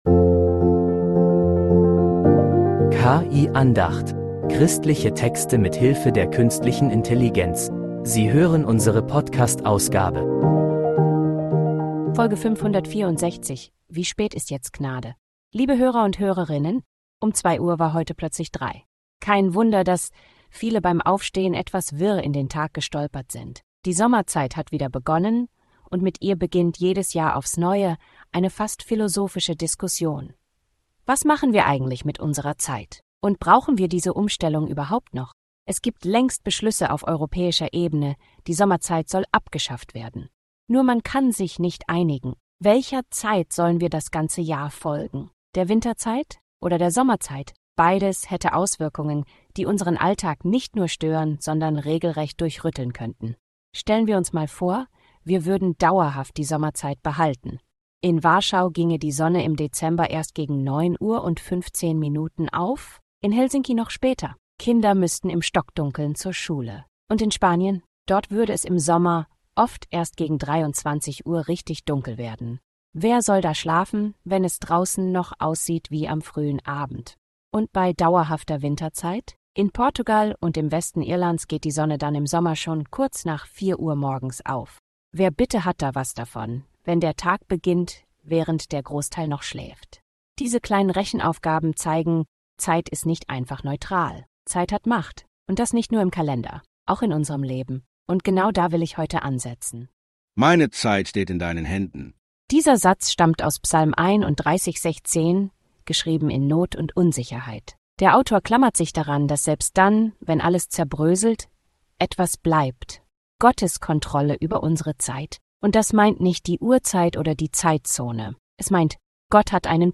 Eine Andacht über Zeit, Hektik und was es heißt, im richtigen Moment zu leben